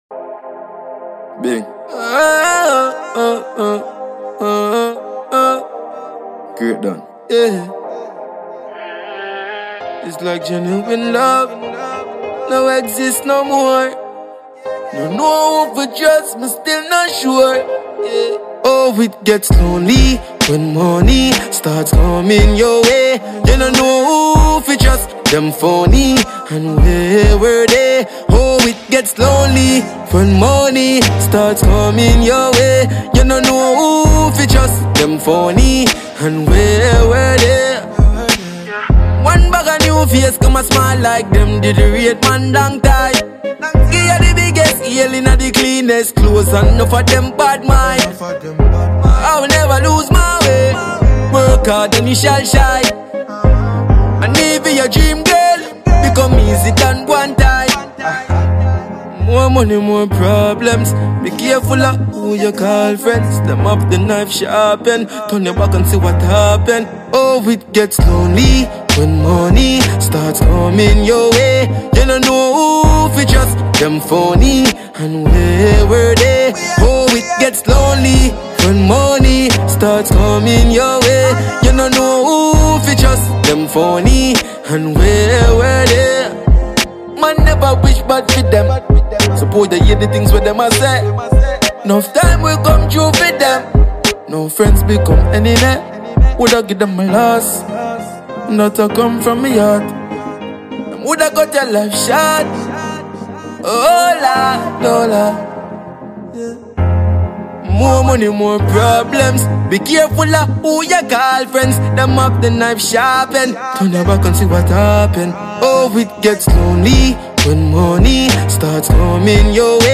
Dancehall/HiphopMusic